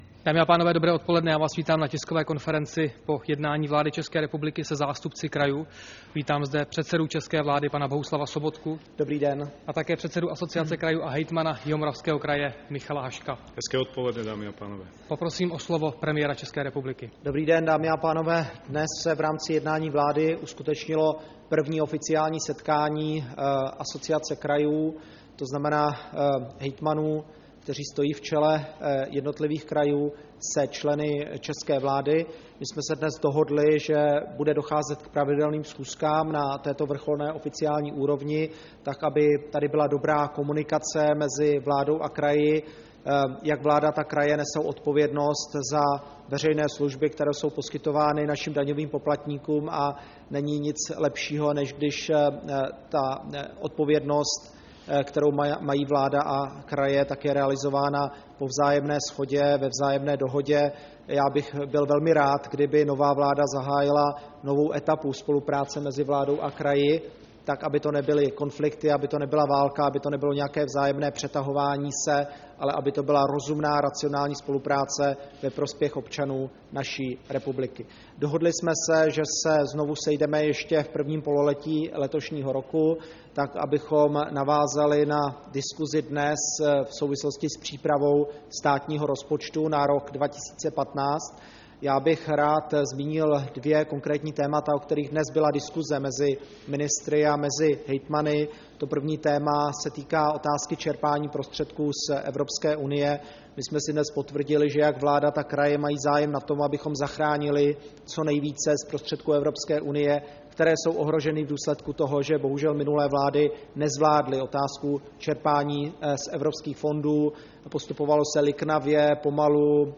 Tisková konference po jednání vlády ČR s Asociací krajů ČR, 26. února 2014